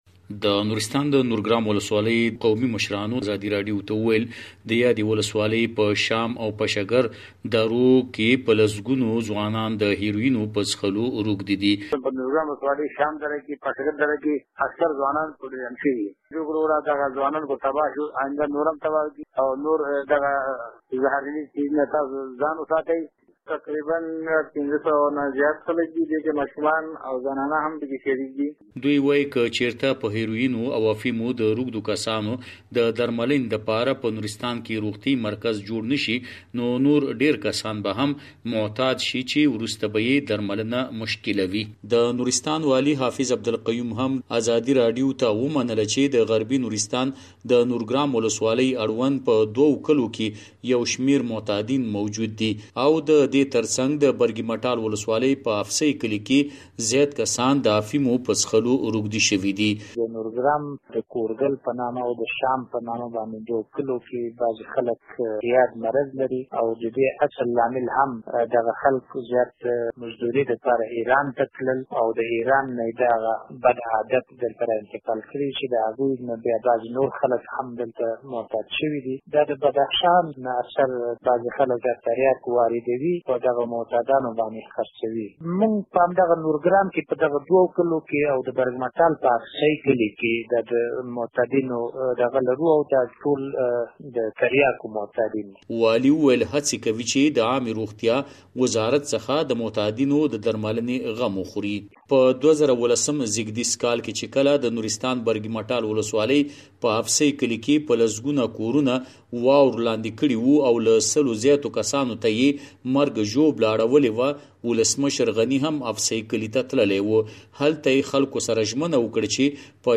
د نورستان راپور